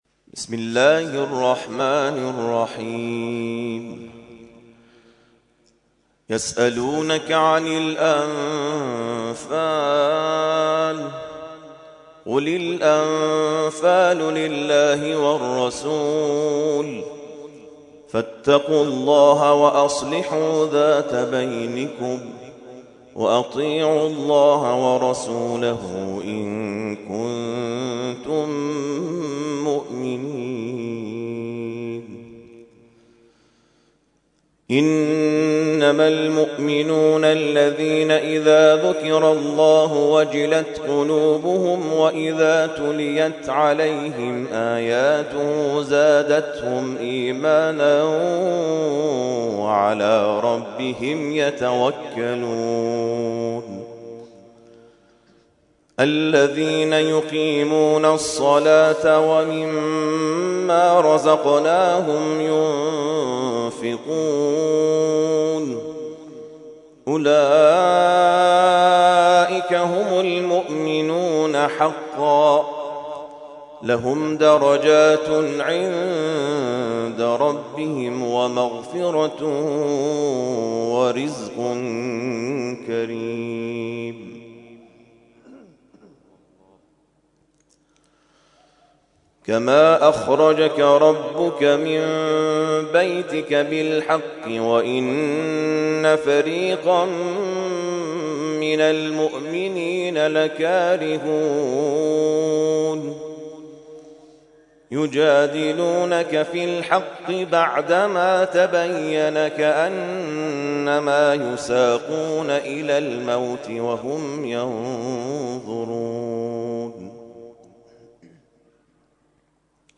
ترتیل خوانی جزء ۹ قرآن کریم در سال ۱۳۹۵